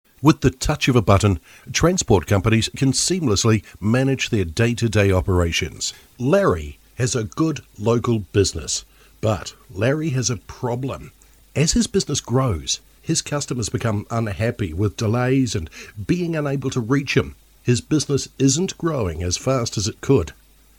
A New Zealand Voice Talent with a sound that’s warm, believable and buttery smooth.
Working from a home based studio in the lower North Island of New Zealand we are able to offer quick turnaround of voice overs, demos, auditions and guide tracks.
Explainers